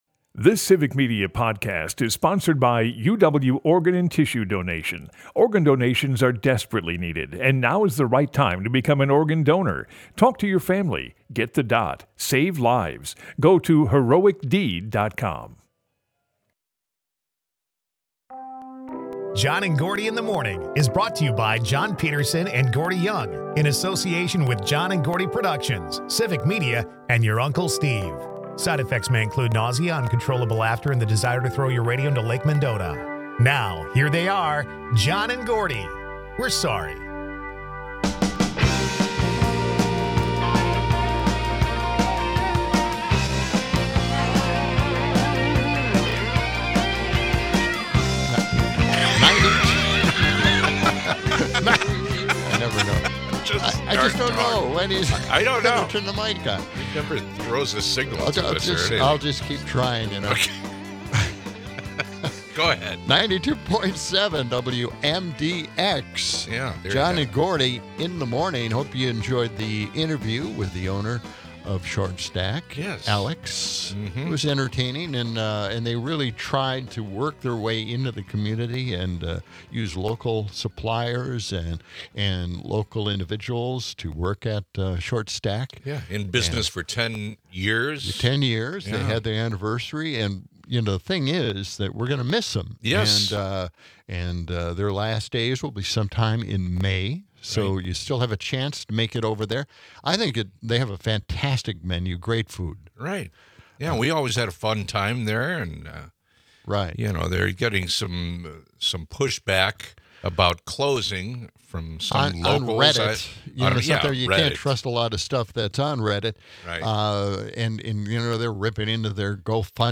In hour two, the guys have a conversation with callers about the new speed limits in Madison, and the cost of updating the signs.